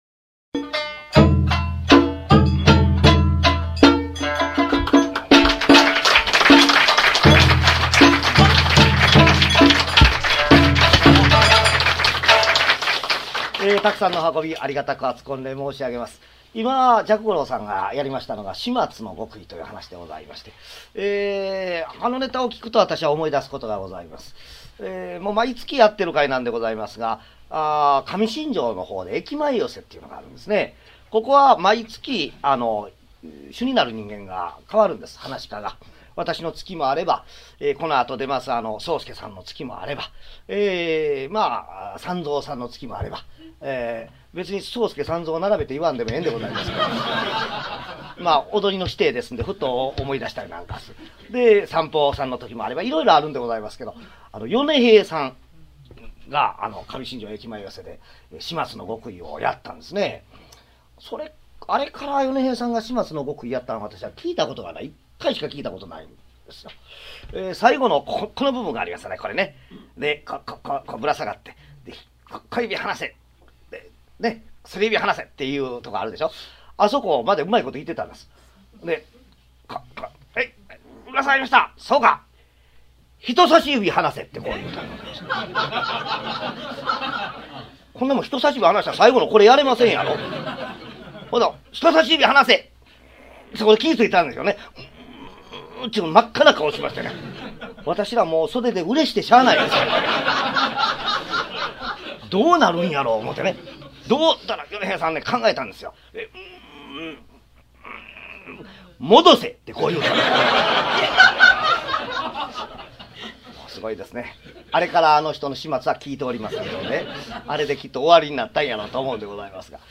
「猫間川寄席」の雰囲気を、そのままで！
約19年間、大阪玉造さんくすホールで、毎月開催されている「猫間川寄席」での、四代目桂文我の口演を収録した落語集。 書籍版「桂文我 上方落語全集」に掲載したネタを、ライブ公演の録音で楽しみ、文字の落語と、実際の口演との違いを再確認していただければ幸いです。